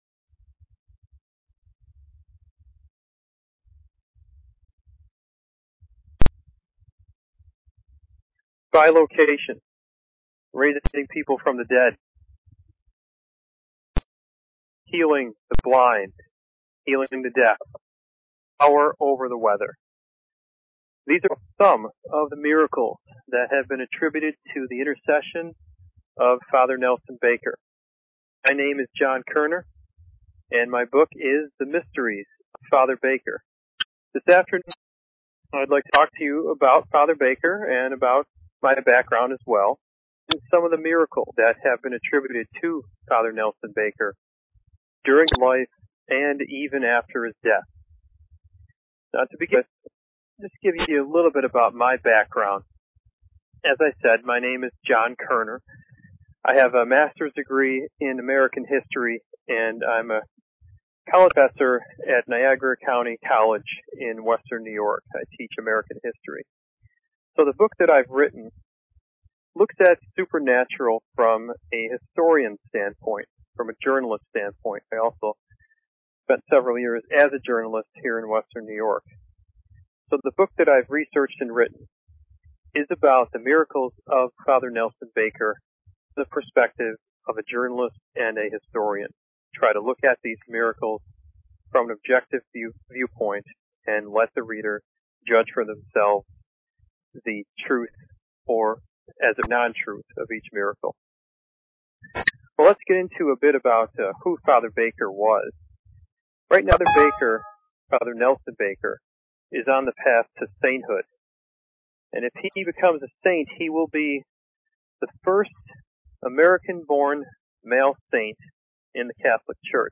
Talk Show Episode, Audio Podcast, Wildcard_Fridays and Courtesy of BBS Radio on , show guests , about , categorized as